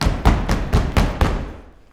121 STOMP4-R.wav